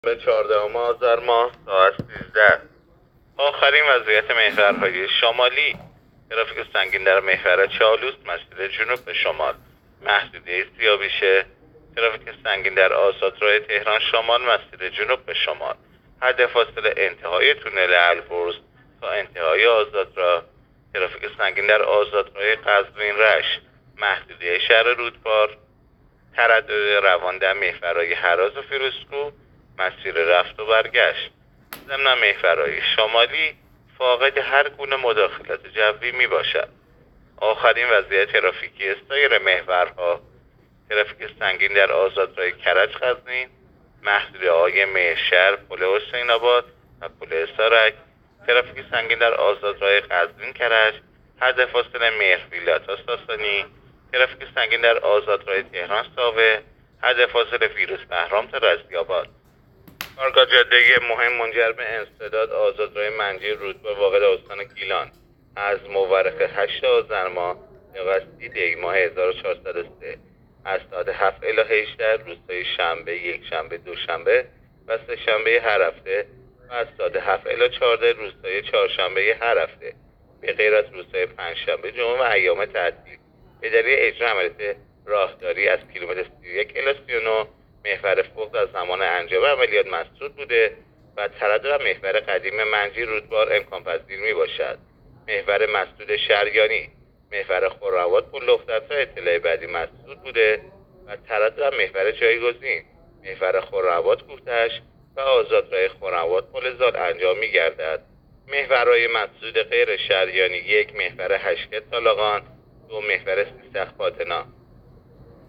گزارش رادیو اینترنتی از آخرین وضعیت ترافیکی جاده‌ها تا ساعت ۱۳ چهاردهم آذر؛